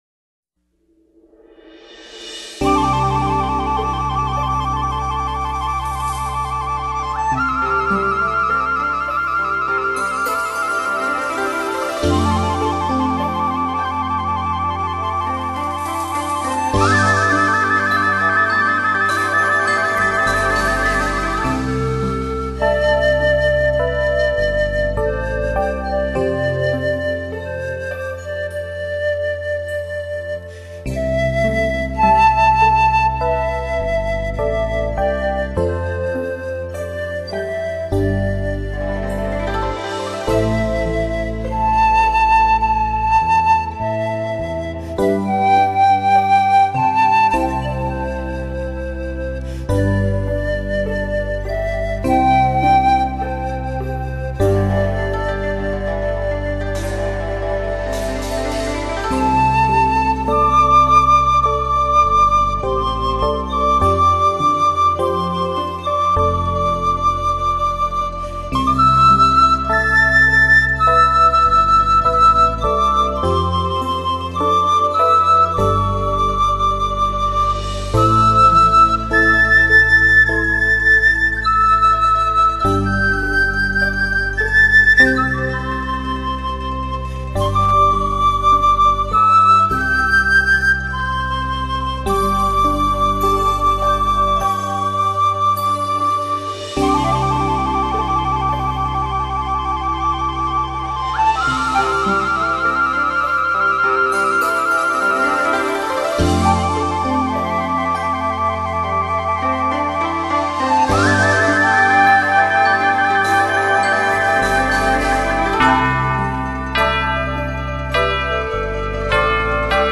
中国传统的乐器来做出充满意境的东方New Age音乐
另外此碟使用了JVC公司的XRCD2技术录音，使得声音厚暖圆润场感开阔，的确是近期New Age音乐的发烧之作！
排笛演奏家